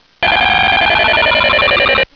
ＶＧＭ：Beep音源